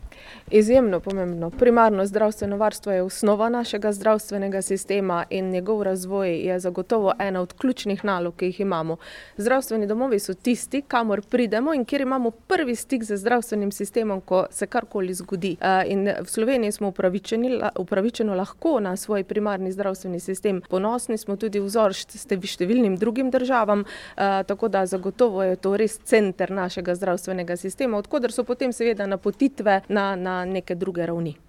Zdravstveno varstvo na primarni ravni je zelo pomembno. Kako zelo, razloži ministrica za zdravje Valentina Prevolnik Rupel, kot Mislinjčanka tudi sama uporabnica storitev skupnega zdravstvenega doma obeh občin: